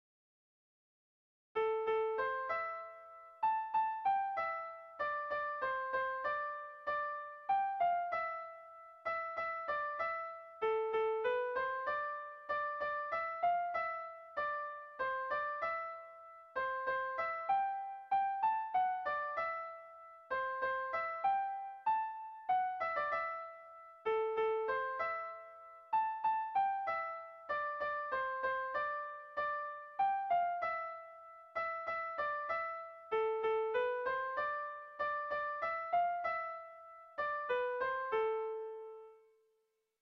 Melodías de bertsos - Ver ficha   Más información sobre esta sección
Sentimenduzkoa
ABD